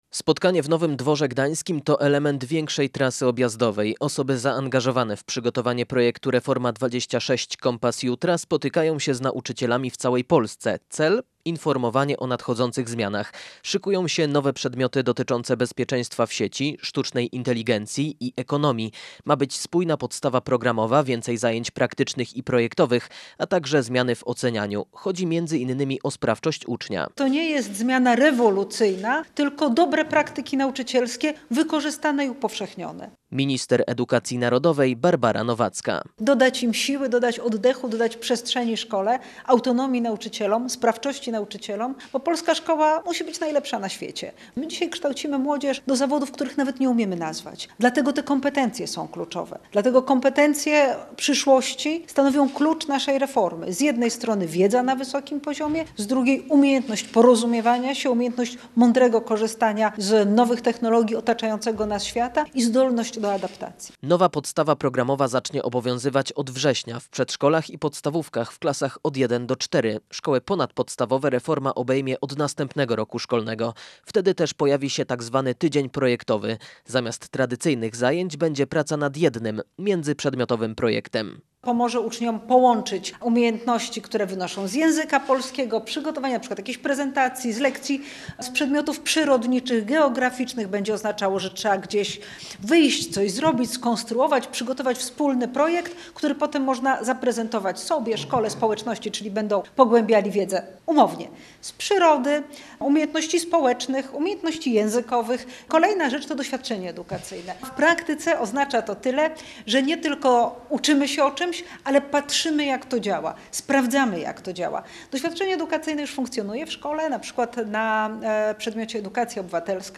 Nowe przedmioty dotyczące bezpieczeństwa w sieci, sztucznej inteligencji i ekonomii, spójna podstawa programowa, więcej zajęć praktycznych i projektowych, a także zmiany w ocenianiu – to niektóre założenia reformy „Kompas Jutra” przygotowywanej przez Ministerstwo Edukacji Narodowej. – Chodzi o sprawczość ucznia – mówiła w Nowym Dworze Gdańskim minister Barbara Nowacka.